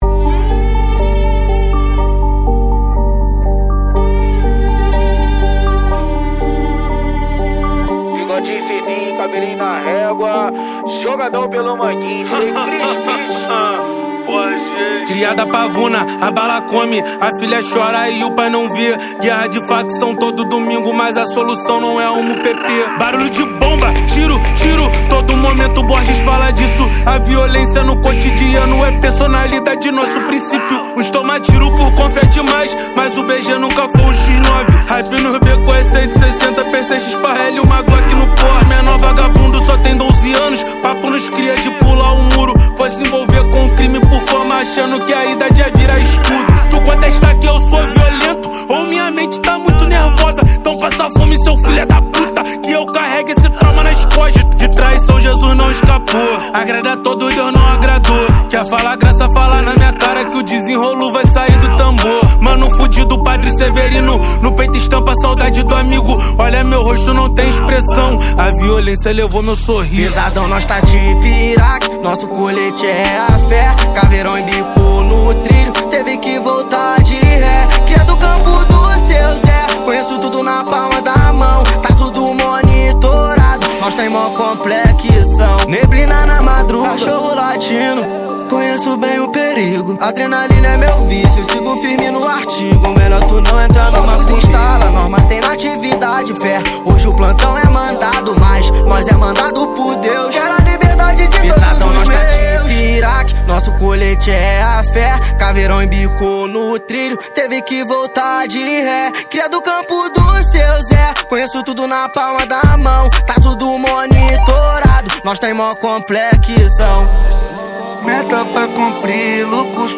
Trap Views